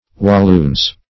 Walloons \Wal*loons"\, n. pl.; sing. Walloon.